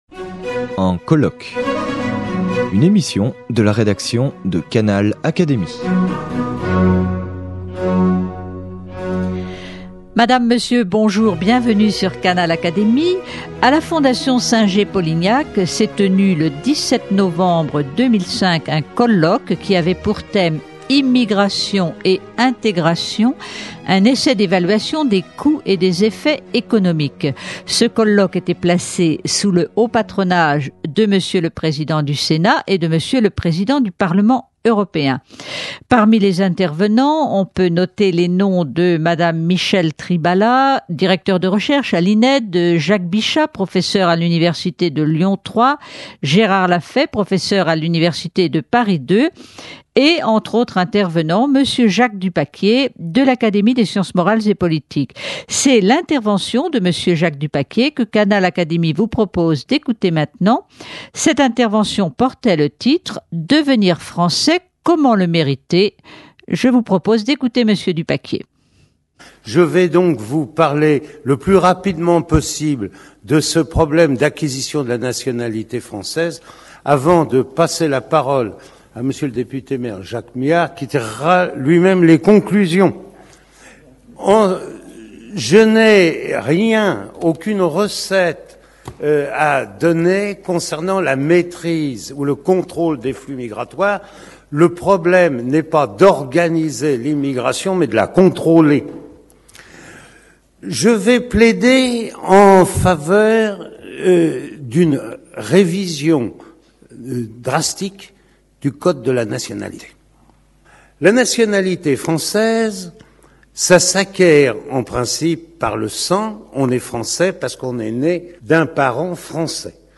Retransmission de la conclusion du colloque placé sous le haut patronage de M. le Président du Sénat et de M. Le Président du Parlement européen à la Fondation Singer-Polignac le 17 novembre 2005.